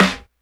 snare01.wav